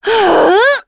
Shamilla-Vox_Casting4.wav